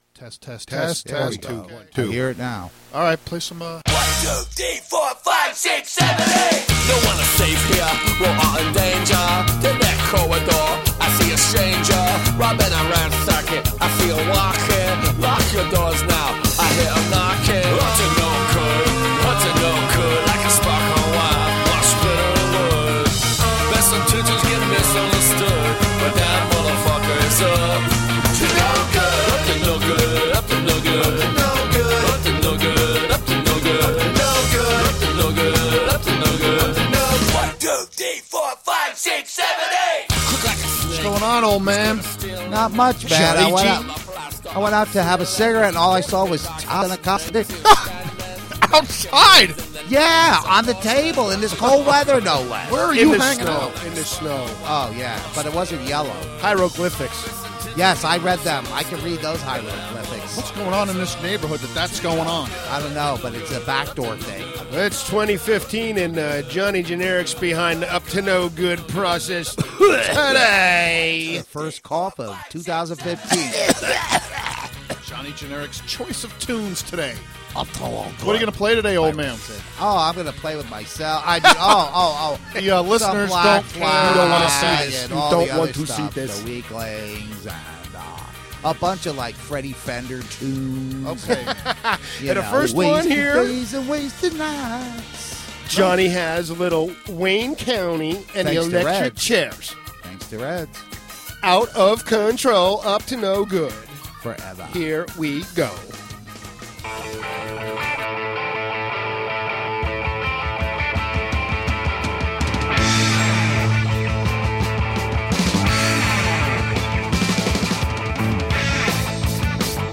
Enjoy the foul shenanigans. Punk rock and definitely some assorted nasties.